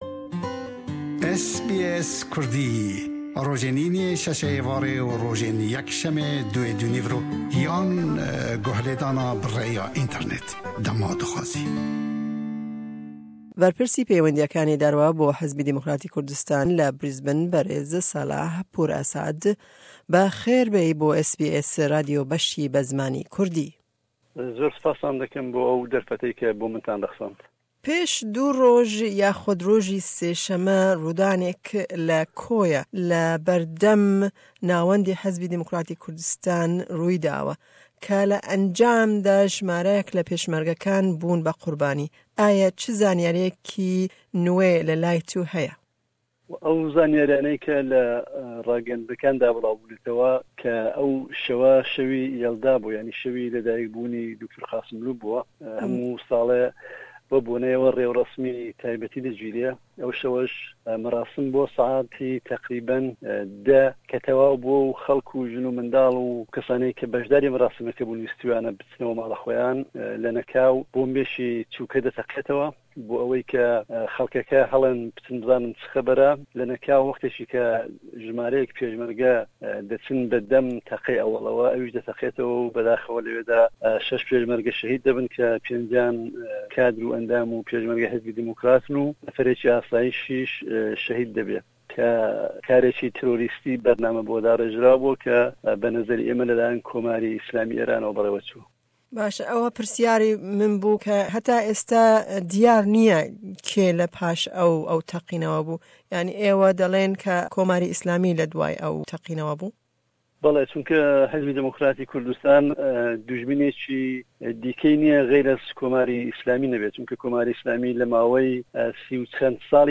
hevpeyvînek